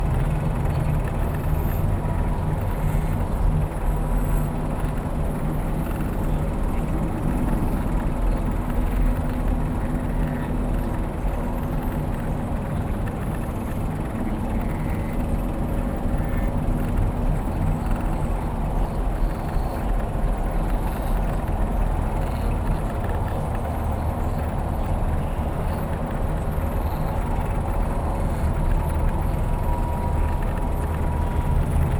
pgs/Assets/Audio/Sci-Fi Sounds/Hum and Ambience/Machine Room Loop 1.wav at master
Machine Room Loop 1.wav